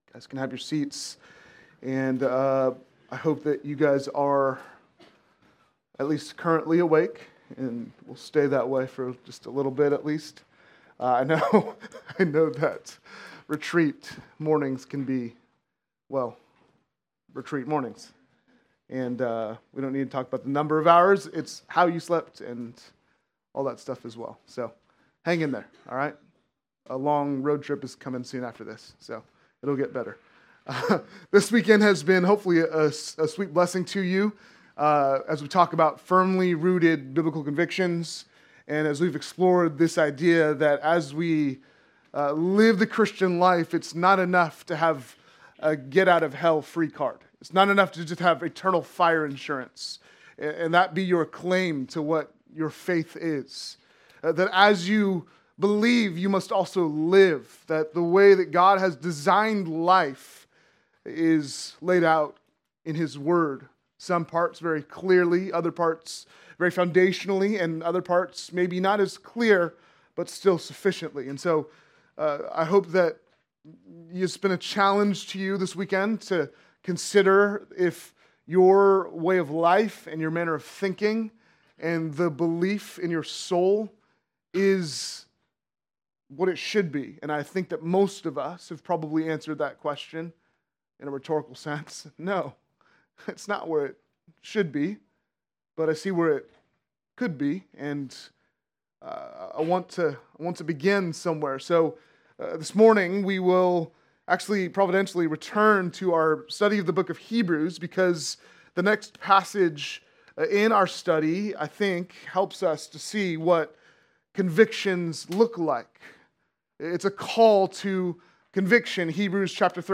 November 9, 2025 - Sermon